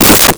Wood Crack 01
Wood Crack 01.wav